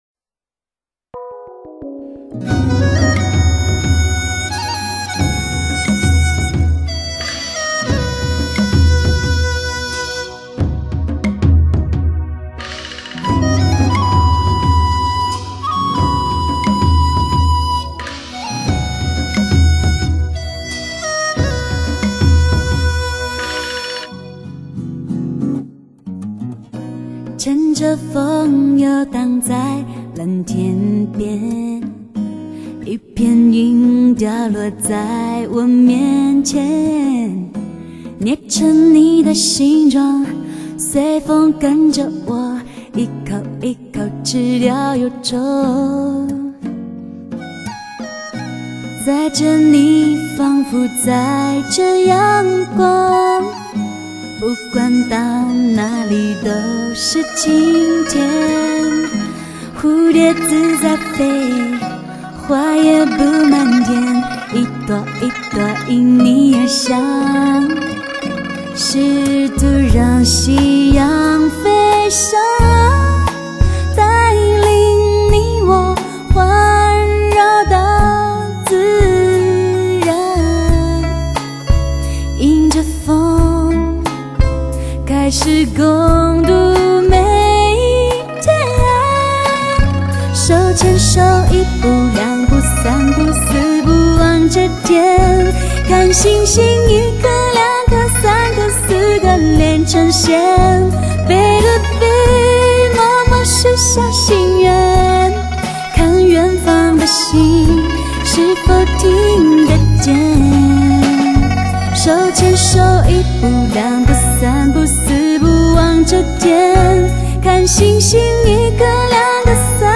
中国首套真正标准的6.1DTS ES音效CD珍藏系列!
神奇的多声道“立体空间”体验，人声、乐器、音效完美合理地分布在各个声道您能体会极致的音乐细节，感受准确的声音移动效果。
这是一张淡雅的专辑，像平湖秋月，波澜不兴。
清澈的声音是海阔天空的怡然，是风雨后的彩虹…